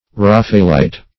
Search Result for " raphaelite" : The Collaborative International Dictionary of English v.0.48: Raphaelite \Raph"a*el*ite\ (r[a^]f"[.a]*[e^]l*[imac]t), n. One who advocates or adopts the principles of Raphaelism.